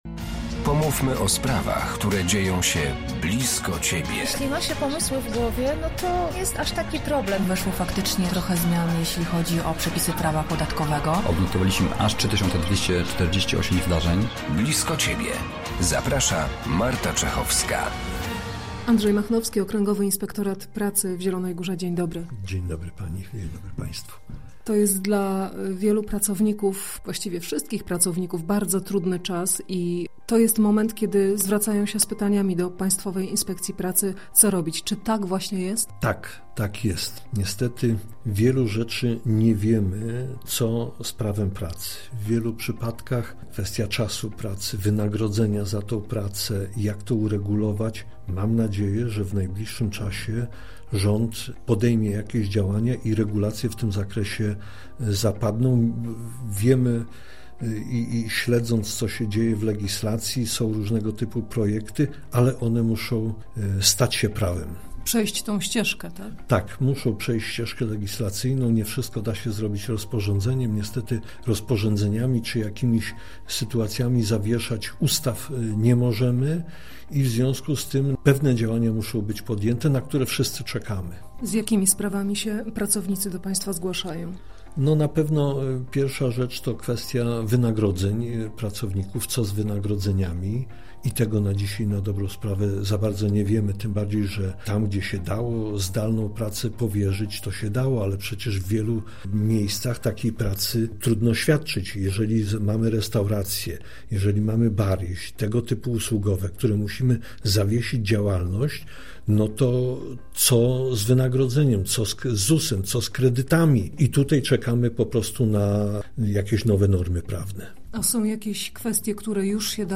Wyjątkowa sytuacja sprawia jednak, że nie wszystkie kwestie można od razu rozstrzygnąć – mówią inspektorzy pracy: